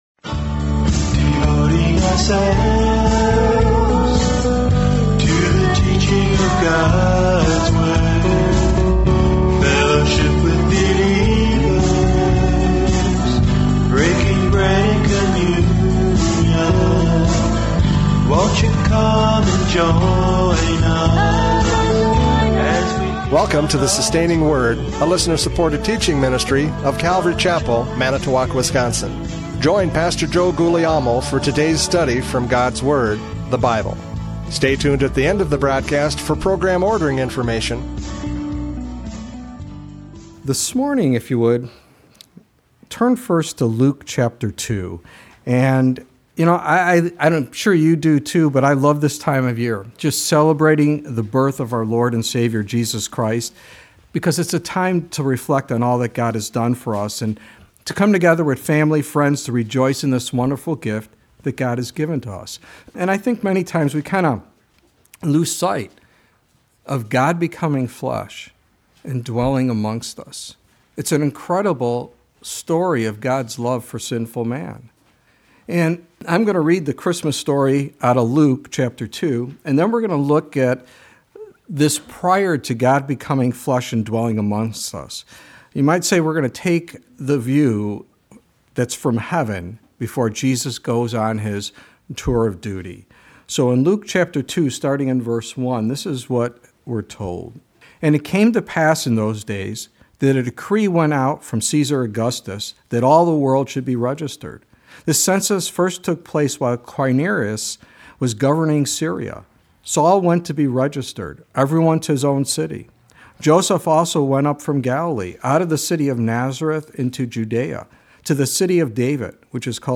Hebrews 10:5-10 Service Type: Radio Programs « John 12:1-8 The Fragrance of Worship!